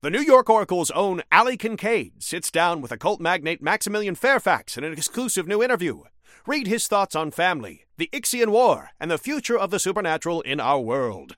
Newscaster_headline_61.mp3